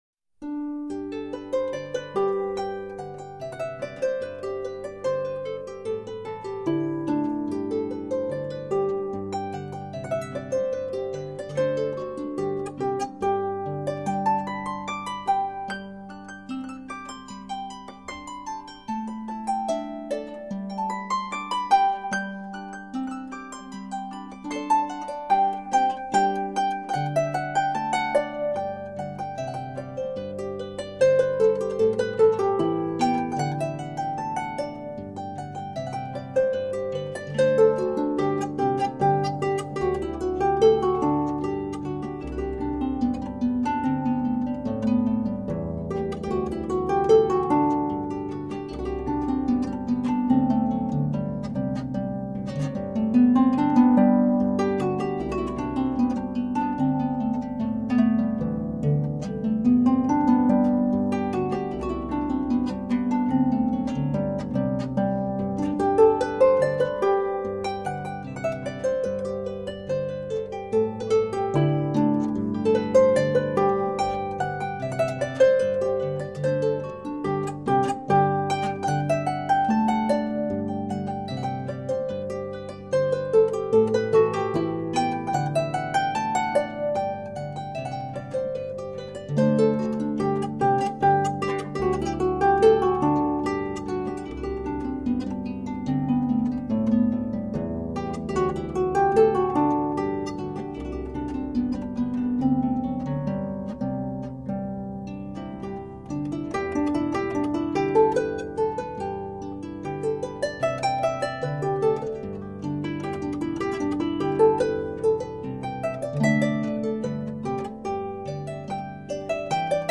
Solo Celtic Harp